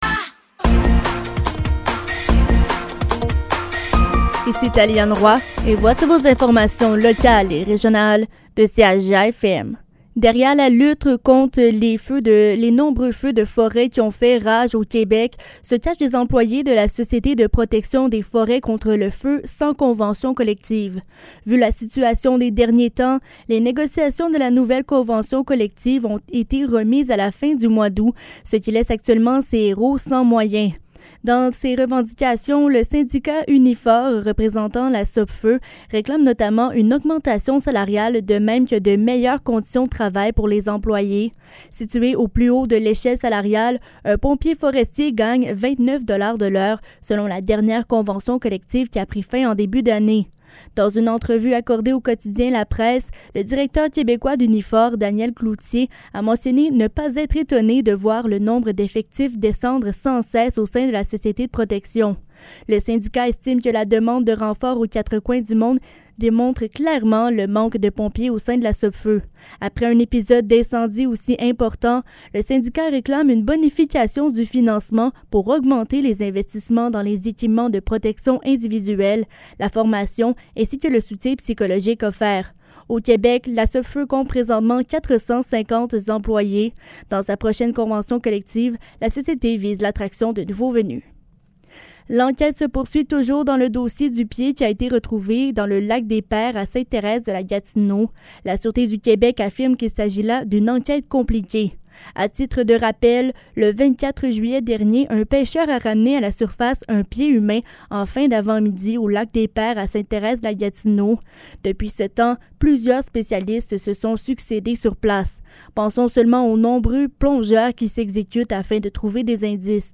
Nouvelles locales - 27 juillet 2023 - 15 h